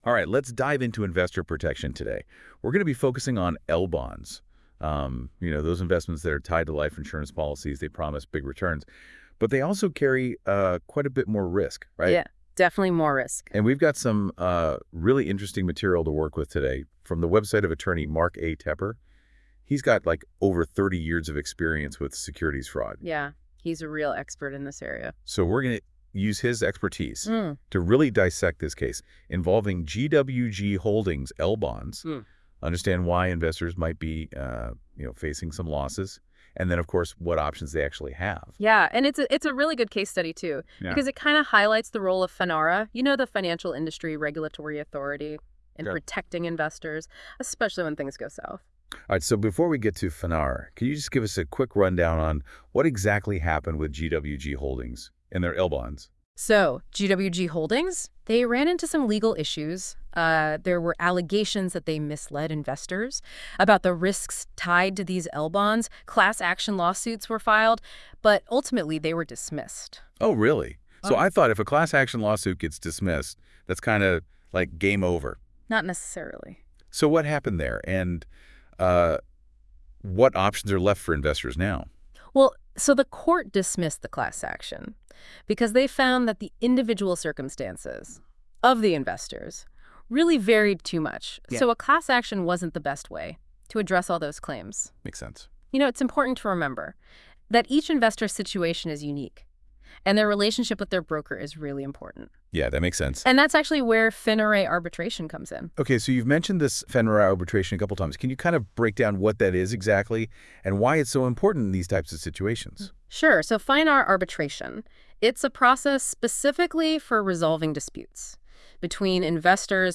The participants are not real people, and the entire AI-powered legal insights podcast session was generated by AI.
Some Mispronunciation: The AI Chatbots have difficulty pronouncing acronyms like FINRA , the Financial Industry Regulatory Authority.